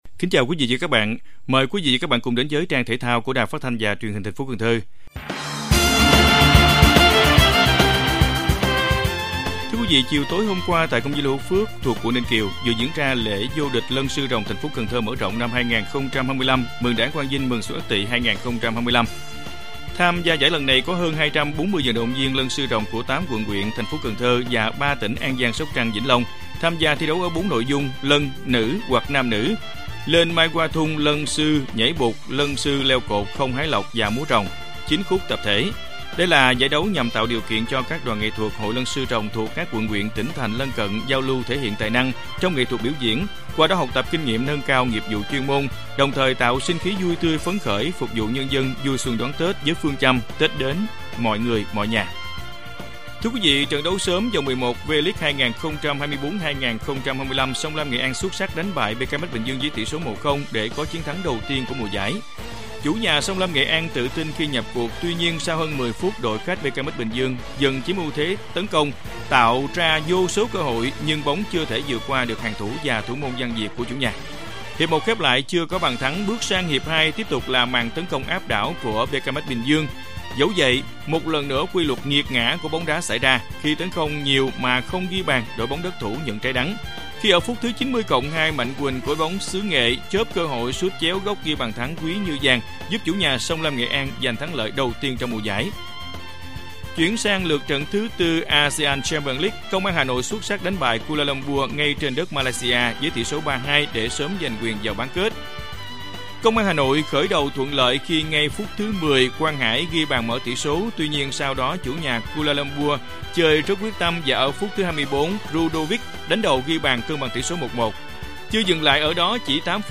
RadioThể thao
Bản tin thể thao 24/1/2025